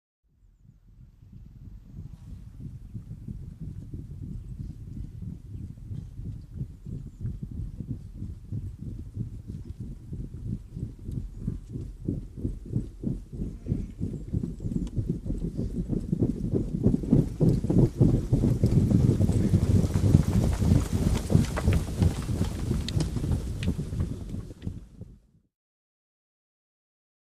Infantry March By Double-time; 200 Men March Up And By Double Time On Hard Mud And Grass Surface, Feet Only, With Light Breaths And Equipment Rattle